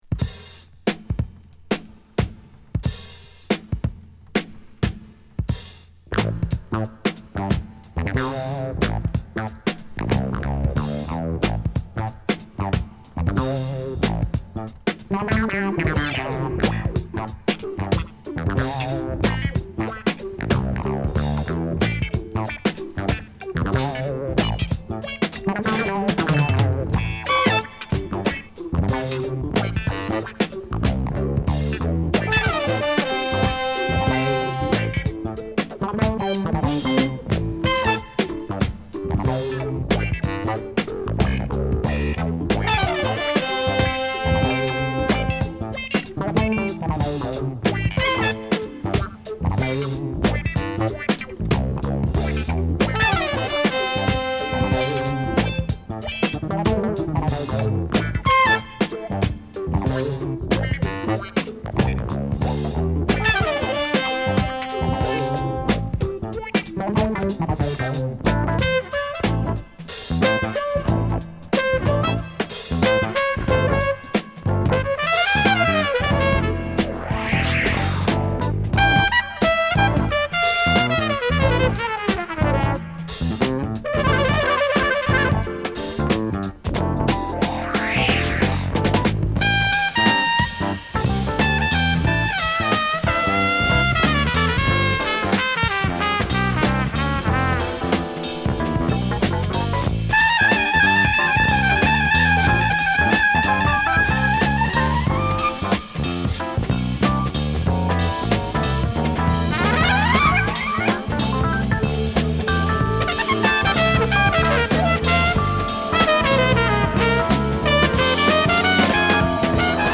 エフェクトで飛ばすSaxや跳びまわるシンセもナイスなジャズ・ファンク！